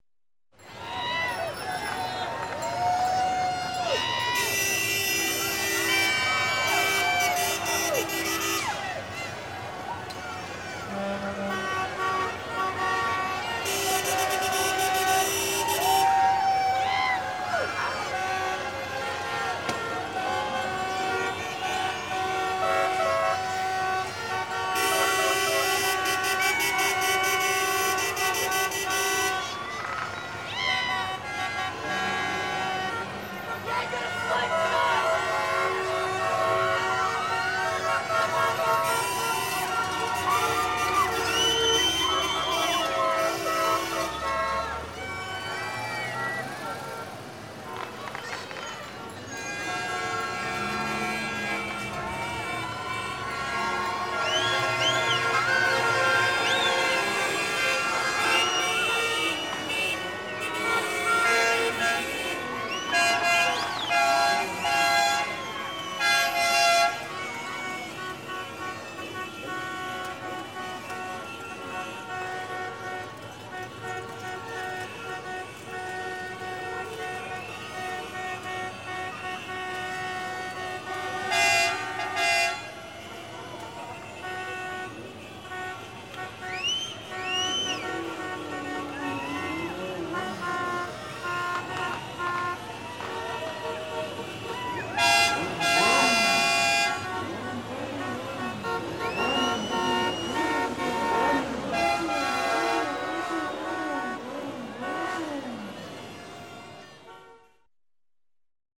Новогодняя атмосфера в городе № 2